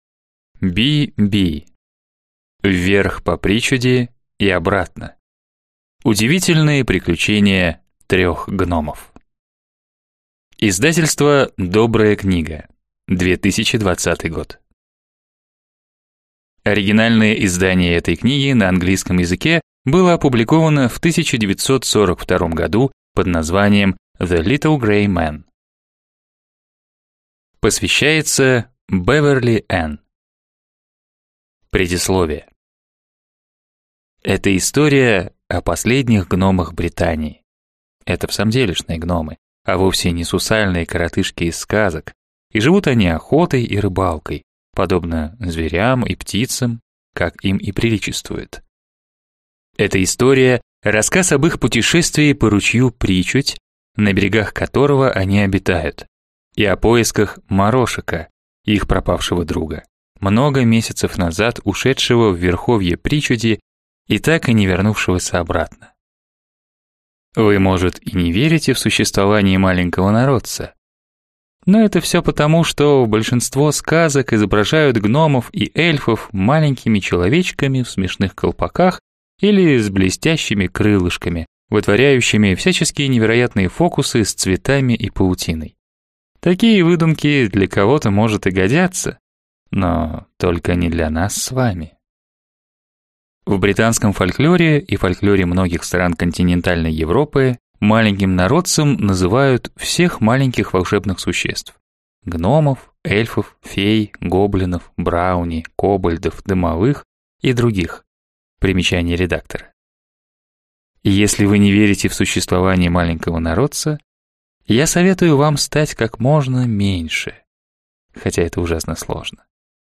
Аудиокнига Вверх по Причуди и обратно. Удивительные приключения трех гномов | Библиотека аудиокниг